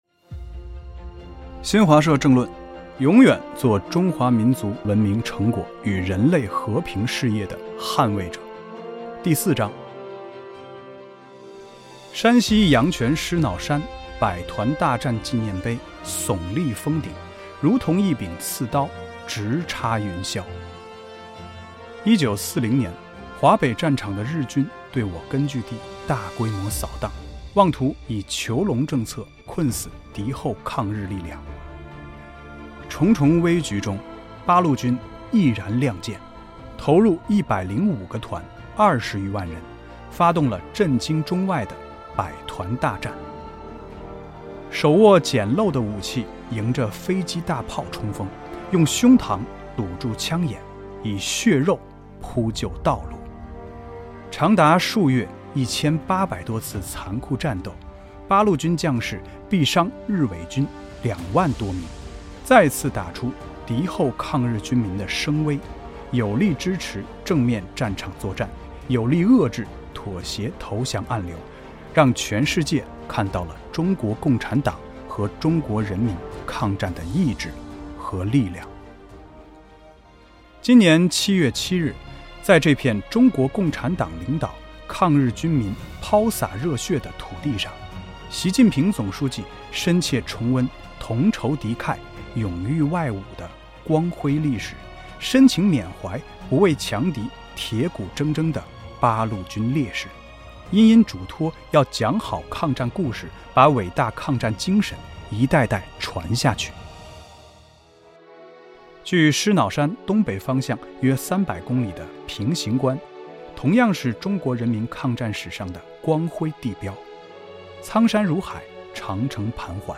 （有声剧版）
我们邀请诵读嘉宾张晚意演播“第四章”。
新华社政论：第四章（诵读版）